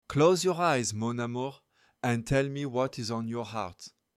Edgy and direct, offering a fresh, fearless perspective.